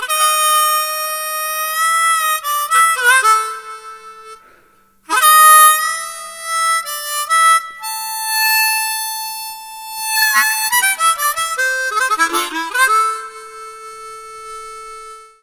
口琴 民谣怀旧 Am 6 8 Time 70bpm
描述：用Lee Oskar Am natural口琴演奏的民谣怀旧曲调。
Tag: 70 bpm Folk Loops Harmonica Loops 1.30 MB wav Key : Unknown